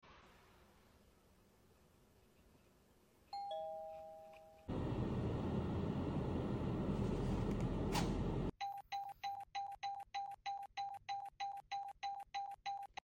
Door Bell✨ Sound Effects Free Download